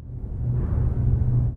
factorypowerup.ogg